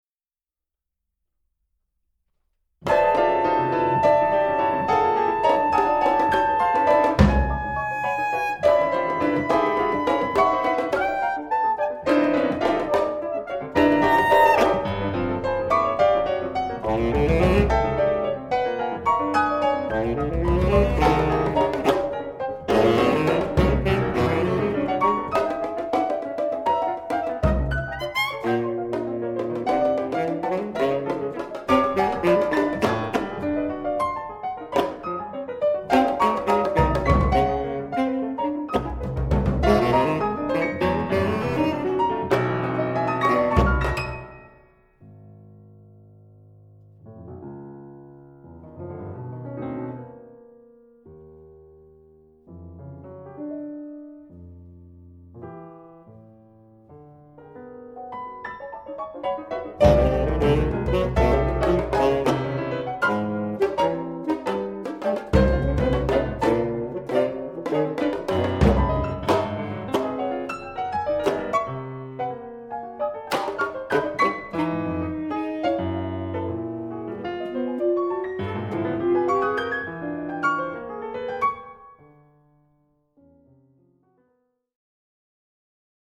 saxophone
percussion
piano